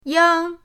yang1.mp3